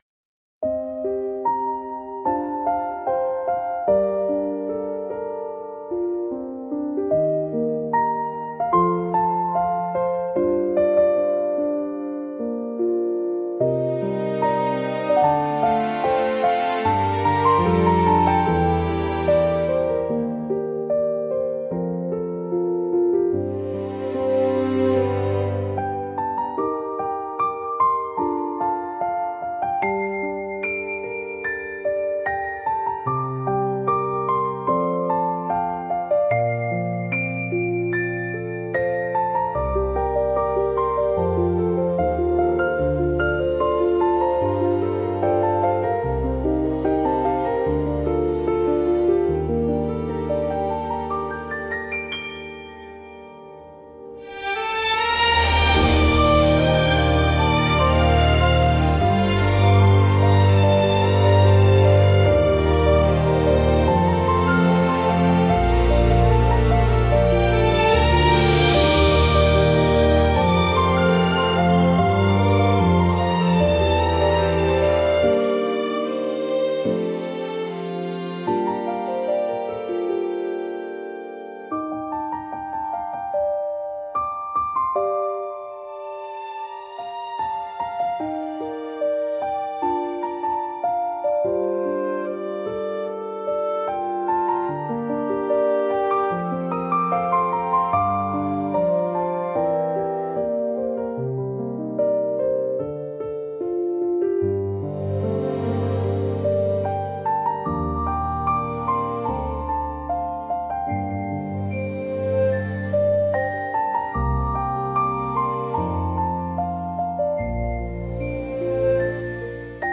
BGM用途としては、失恋や別れ、感傷的な場面、深い内省や静かな悲しみを演出したいシーンに最適です。